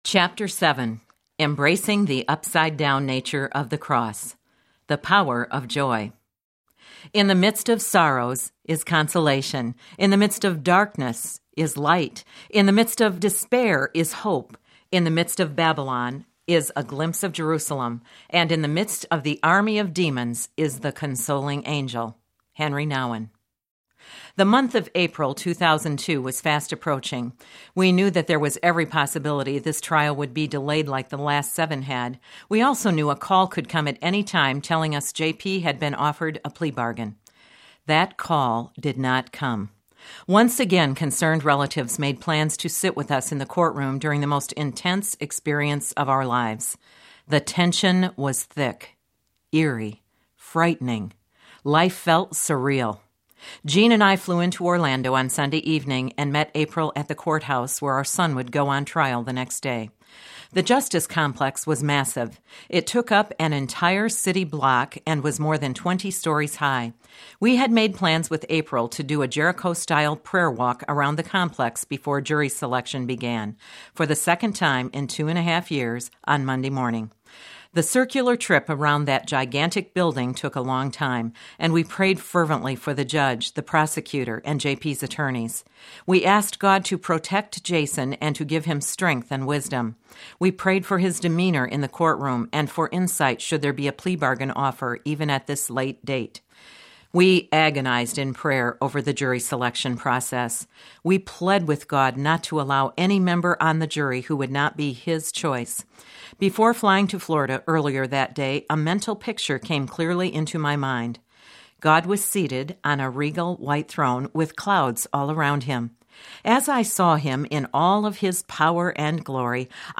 When I Lay My Isaac Down Audiobook
6 Hrs. – Unabridged